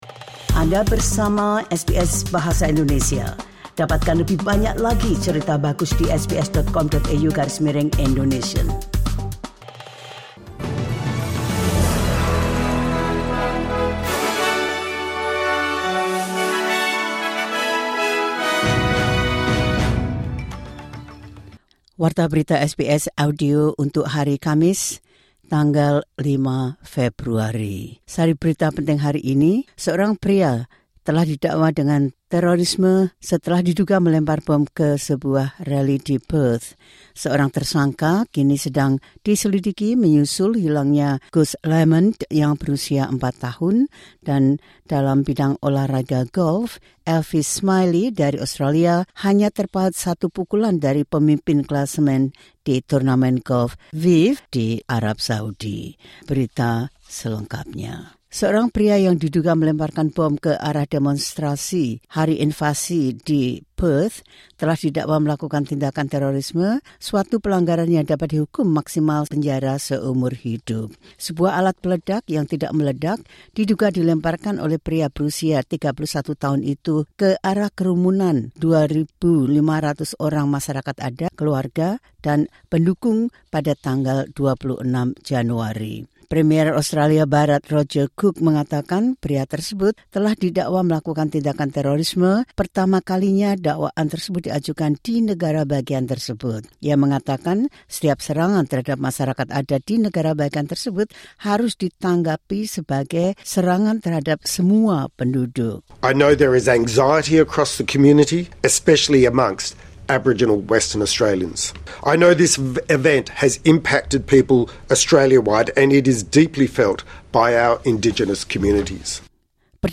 Berita terkini SBS Audio Program Bahasa Indonesia – Kamis - 05 Februari 2026